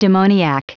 Prononciation du mot demoniac en anglais (fichier audio)